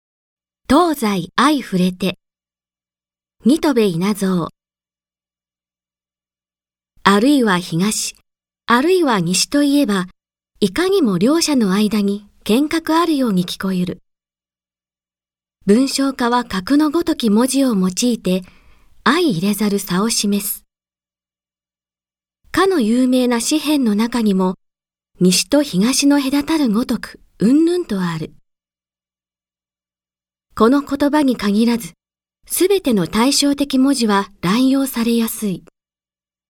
朗読ＣＤ　朗読街道143「東西相触れて・教育家の教育・教育の最大目的」
朗読街道は作品の価値を損なうことなくノーカットで朗読しています。